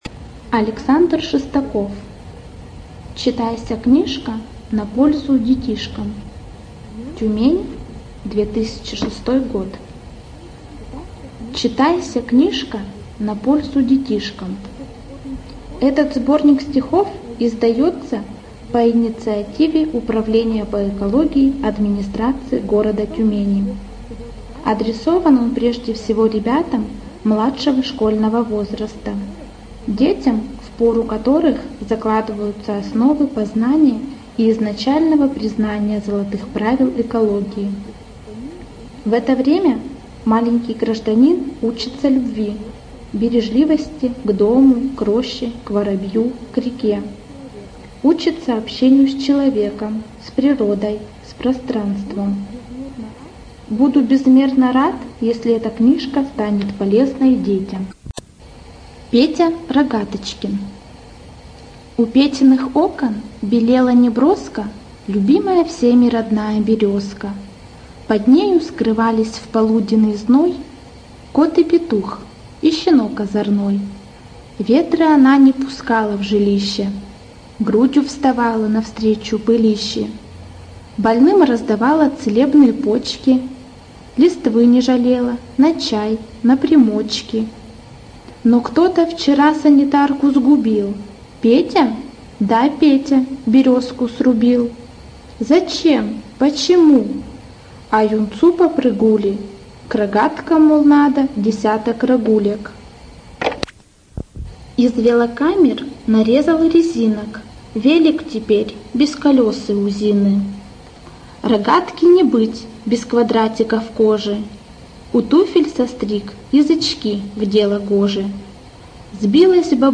Студия звукозаписиТюменская областная библиотека для слепых